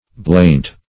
bleynte - definition of bleynte - synonyms, pronunciation, spelling from Free Dictionary Search Result for " bleynte" : The Collaborative International Dictionary of English v.0.48: Bleynte \Bleyn"te\, imp. of Blench .